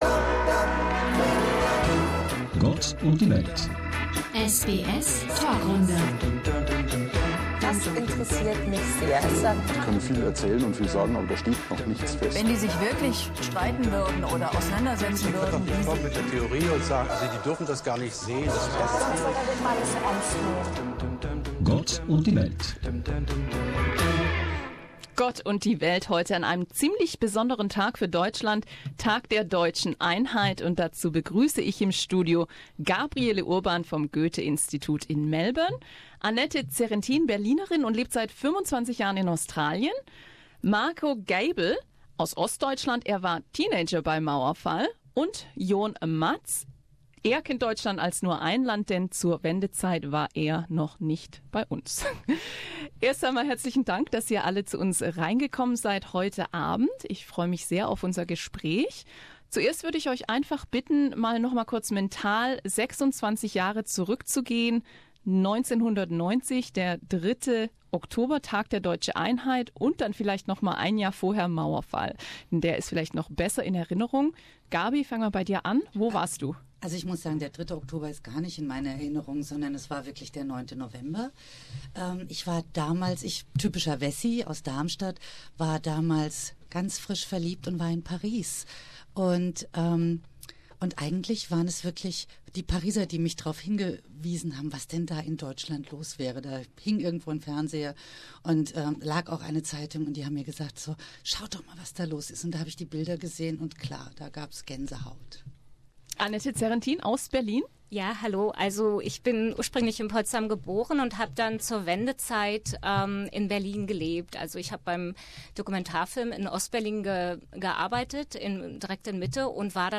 SBS-Panelrunde: Deutsche Wiedervereinigung